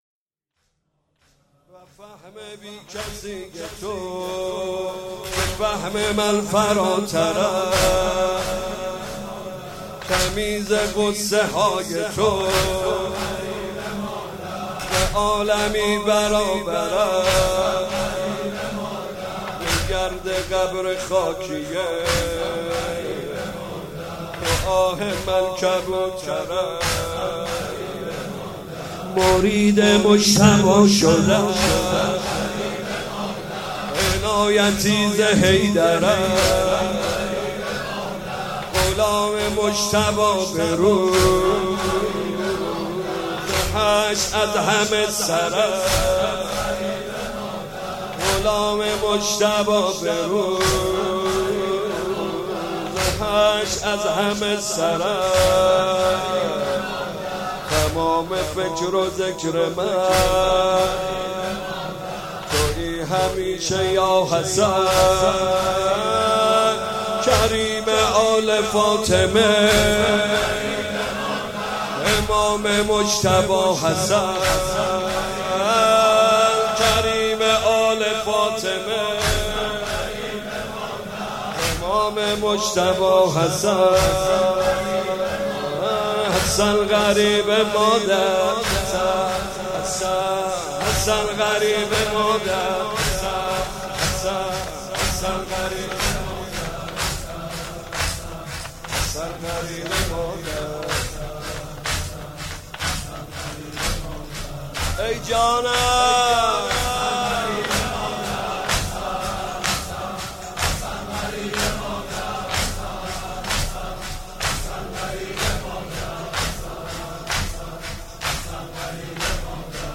6 صفر 96 - شب سوم - واحد تک - کریم آل فاطمه امام مجتبی حسن
صفر المظفر
محمدرضاطاهری واحد مداحی 6 صفر 96 شب سوم واحد تک کریم آل فاطمه امام مجتبی حسن